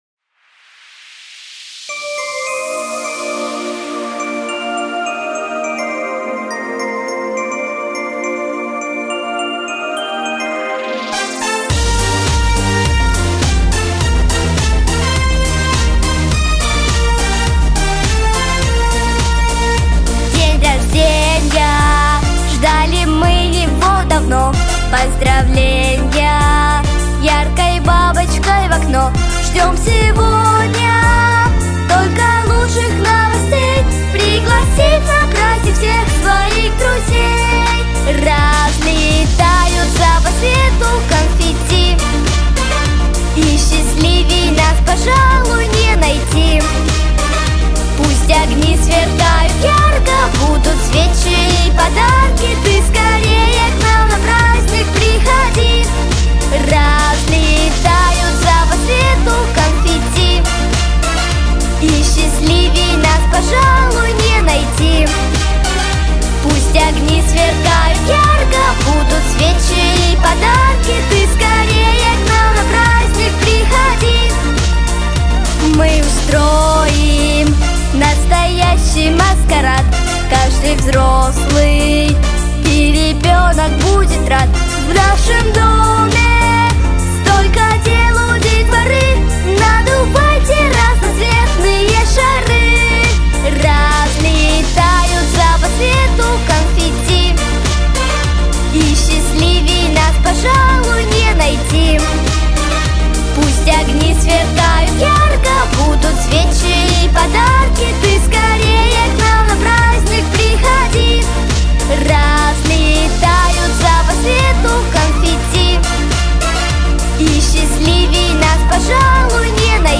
🎶 Детские песни / День рождения 🎂